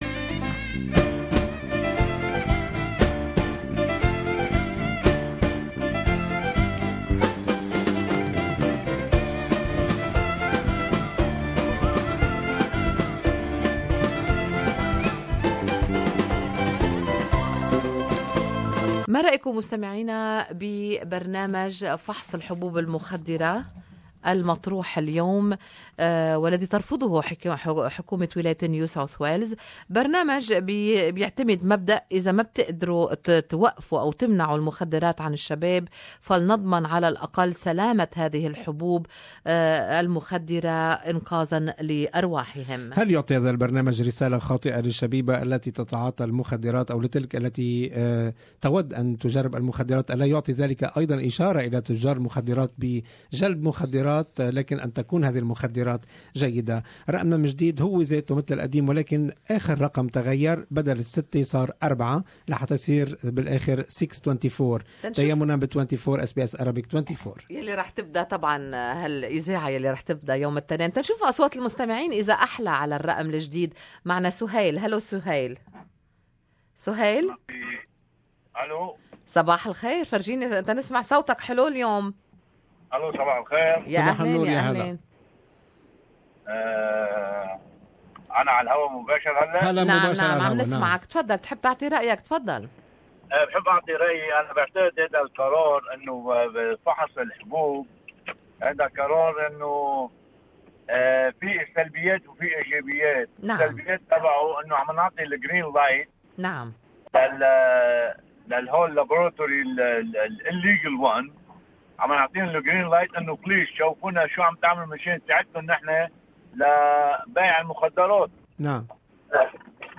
Talkback listeners opinions .